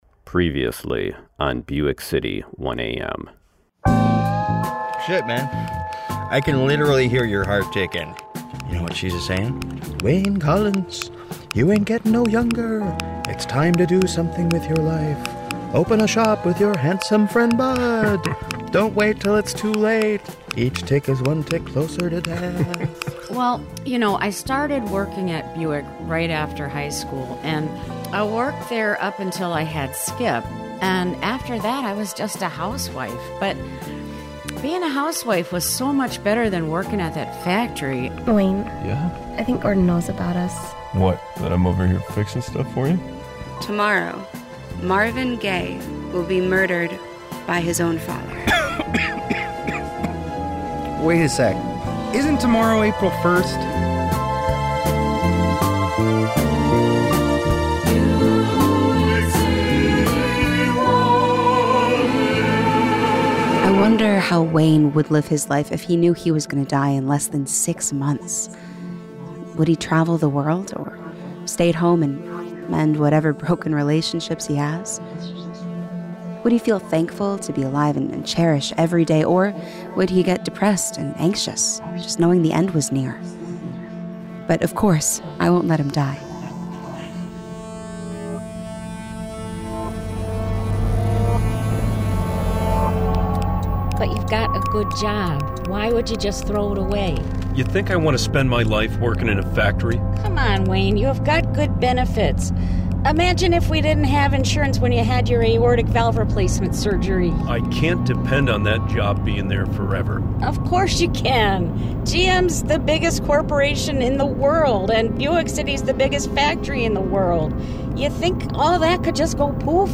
A special broadcast of two podcast/radio opera wor...